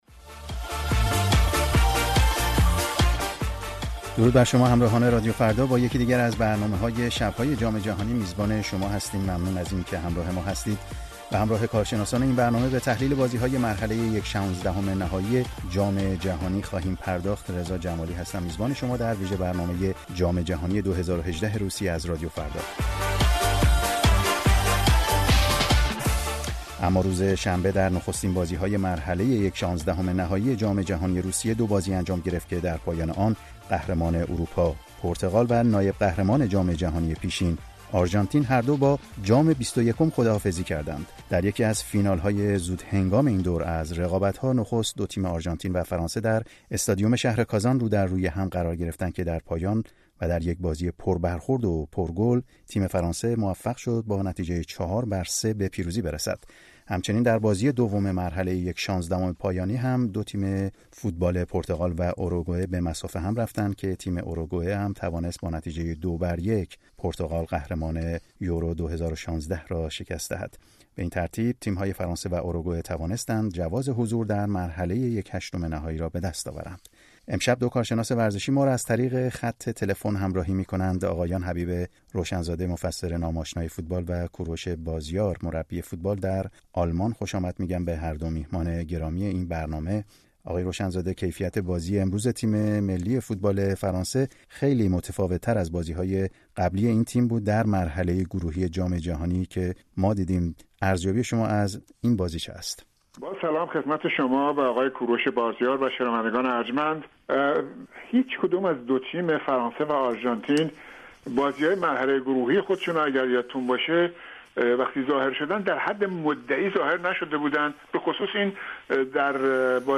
میزگرد بررسی بازی‌های جام جهانی فوتبال در روسیه ۲۰۱۸